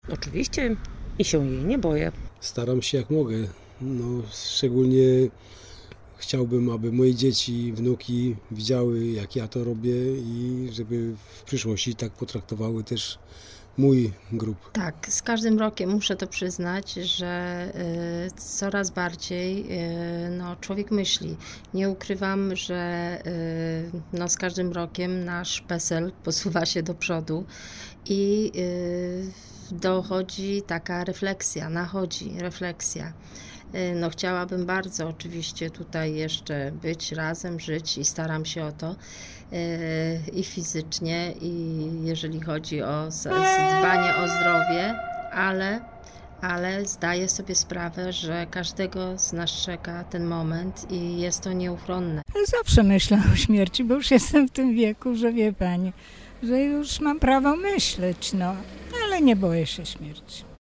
Zapytaliśmy naszych rozmówców czy myślą o swojej własnej śmierci.